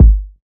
KICK.80.NEPT.wav